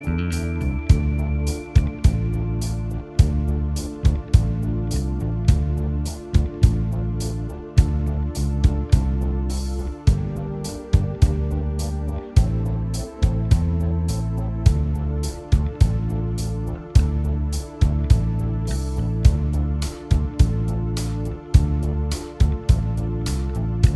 Minus Guitars Rock 4:47 Buy £1.50